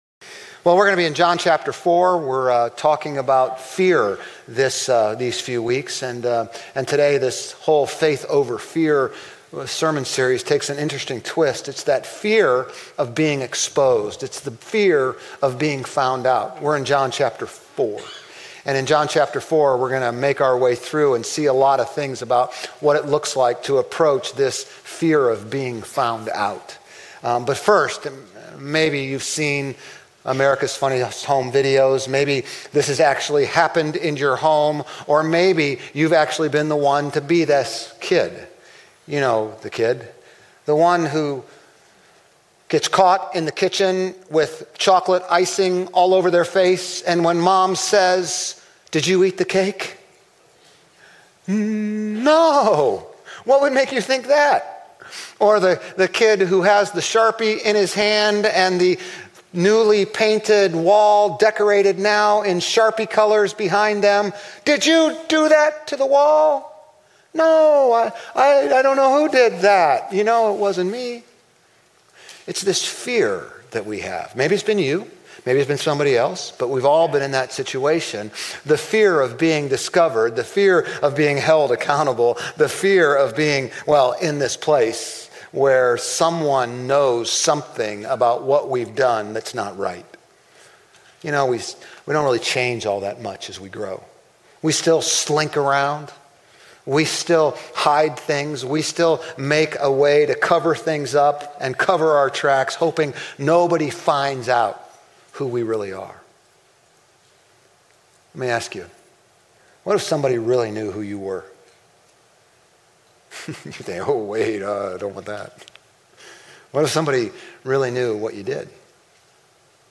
Grace Community Church Old Jacksonville Campus Sermons 5_18 Old Jacksonville Campus May 19 2025 | 00:32:16 1x 00:00 / 00:32:16 Subscribe Share RSS Feed Share Link Embed